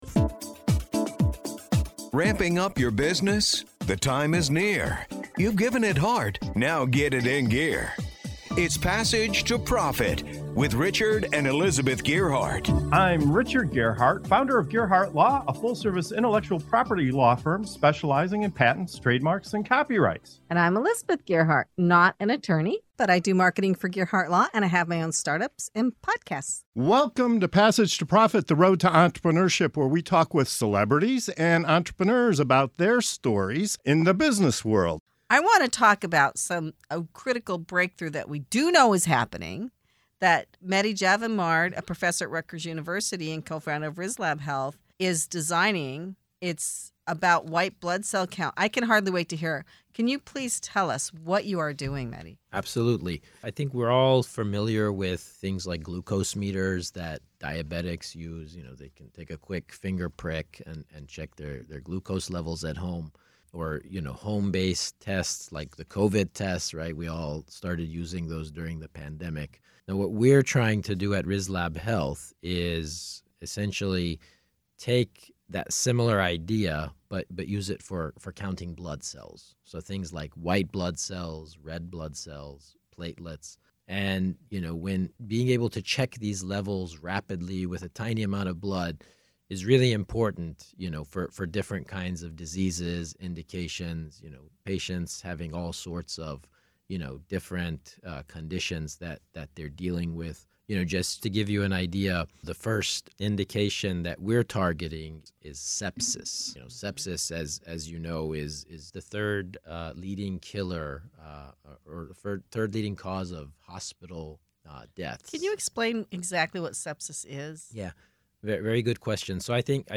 Passage to Profit Show interview